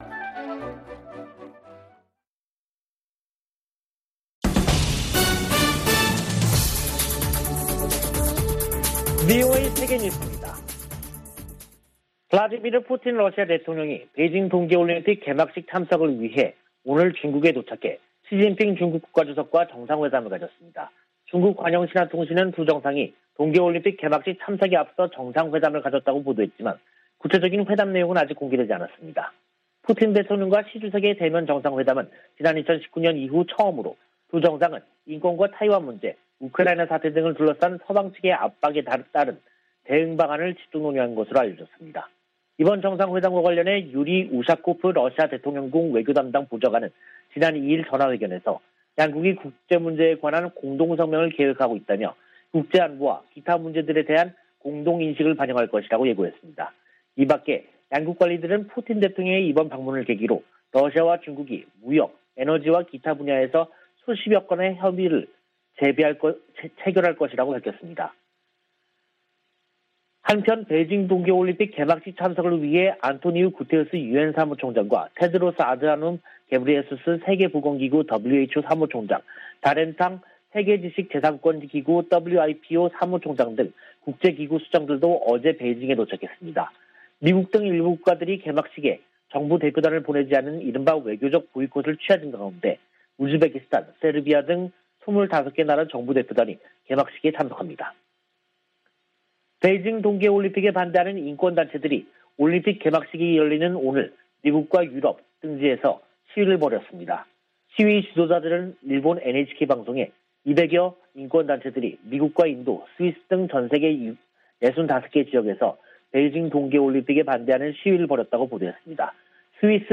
VOA 한국어 간판 뉴스 프로그램 '뉴스 투데이', 2022년 2월 4일 3부 방송입니다. 미 국무부는 북한의 탄도미사일 발사에 대응해 동맹과 파트너는 물론, 유엔과 협력하고 있다고 밝혔습니다. 미군 당국이 일본·호주와 실시 중인 연합 공중훈련의 목적을 설명하면서 북한을 거론했습니다. 호주가 북한의 최근 잇단 미사일 발사를 규탄하며 대량살상무기와 탄도미사일 추구는 국제 평화와 안보에 중대한 위협이라고 지적했습니다.